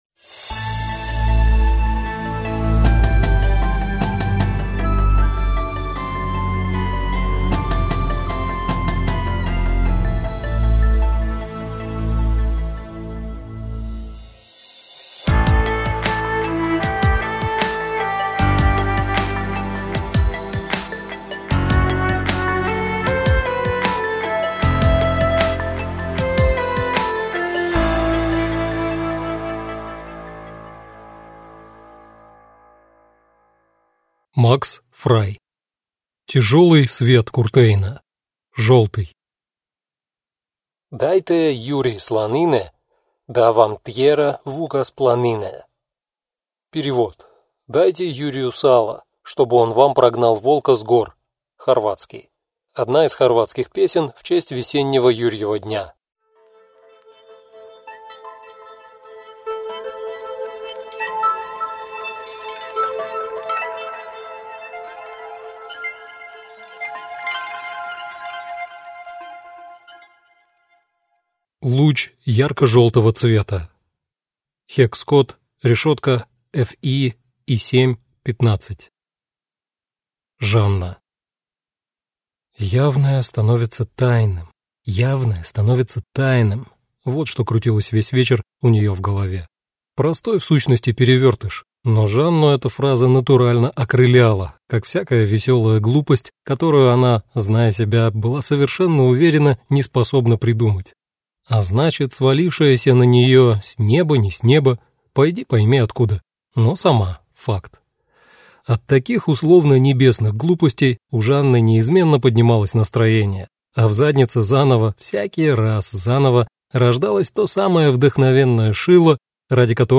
Аудиокнига Тяжелый свет Куртейна. Желтый - купить, скачать и слушать онлайн | КнигоПоиск